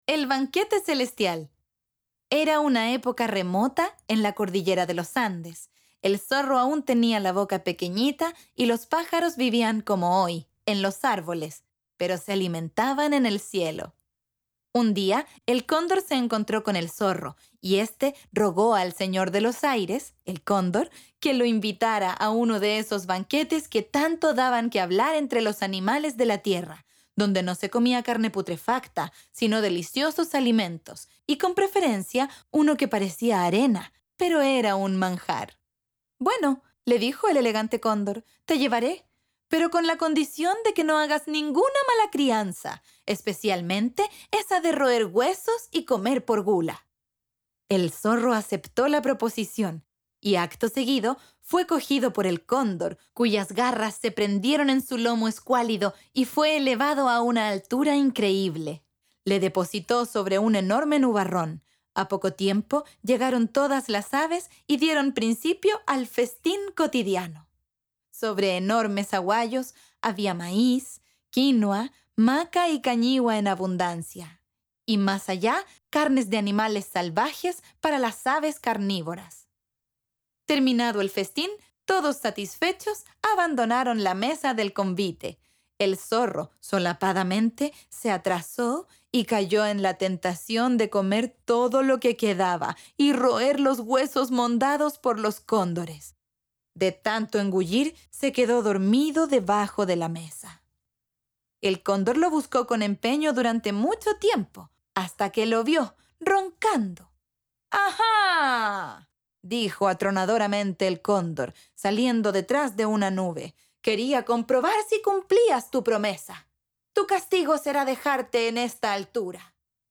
Audiocuentos